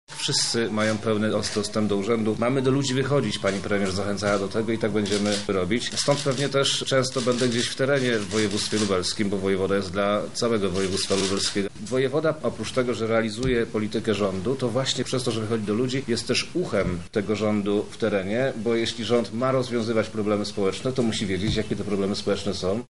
Urząd będzie otwarty na ludzi – mówi wojewoda lubelski, Przemysław Czarnek.